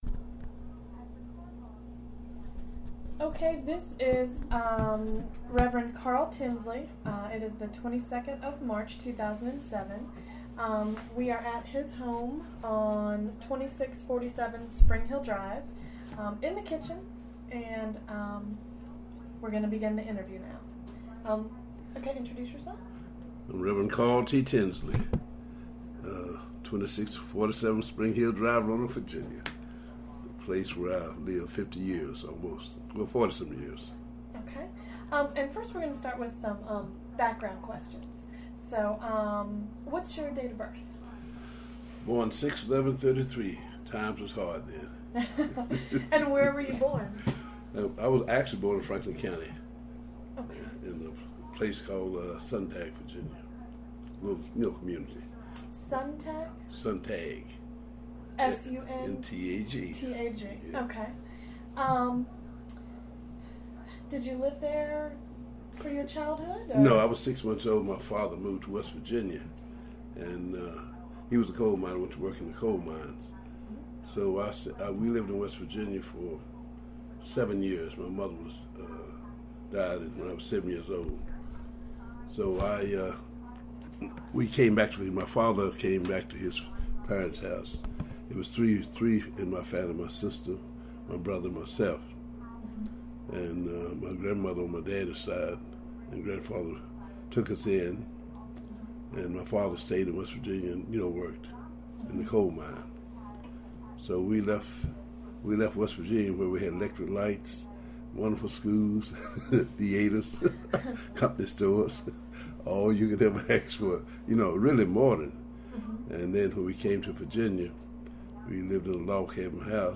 Neighborhood Oral History Project